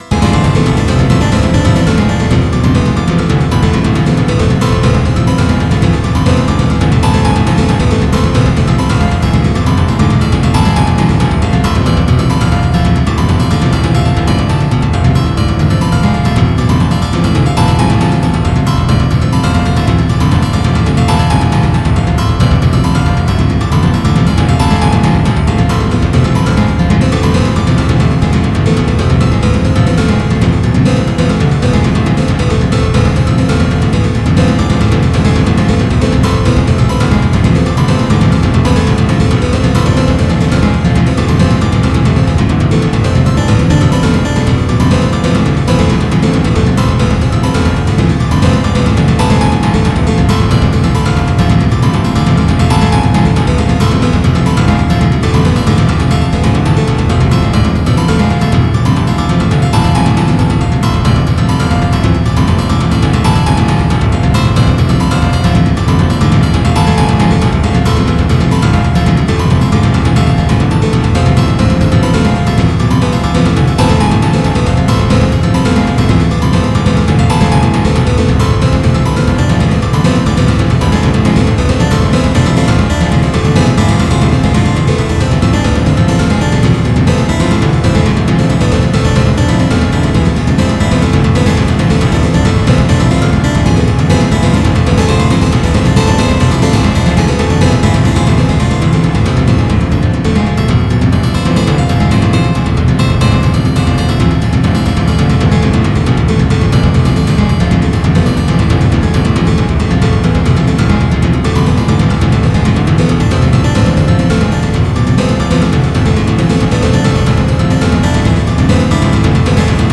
エレクトリック・アコースティック・ギターを使用しての
即興的タッピング演奏の仮想ライブ。
アコースティック・ギター音楽愛好家、現代音楽愛好家、即興音楽愛好家はもちろんのこと、
with both hands tapping technique
this is the multi-layered simulated guitars album.